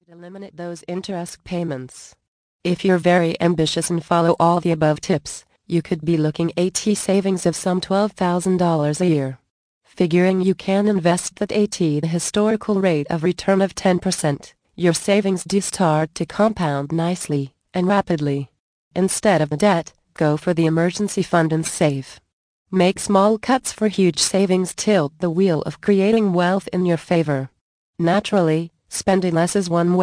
Thank you for your interest in this audio report.